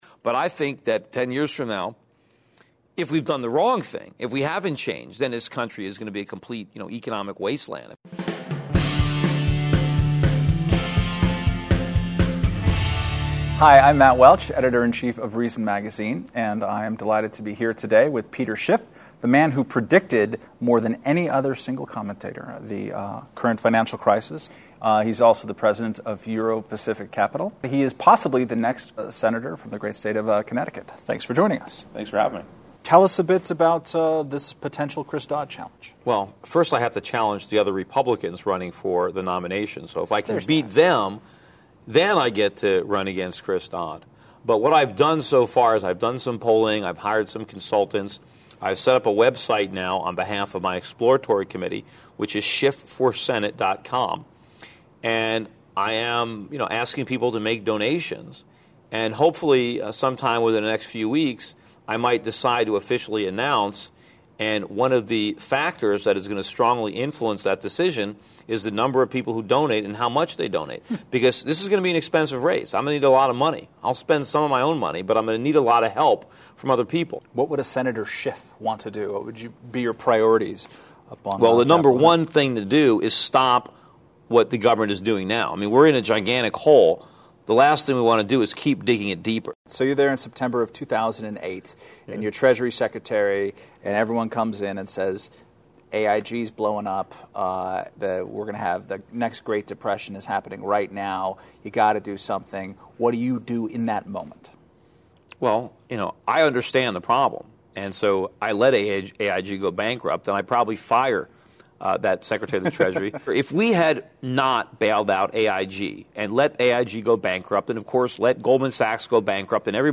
interview
shot at FreedomFest in Las Vegas just days after Schiff announced the formation of his exploratory committee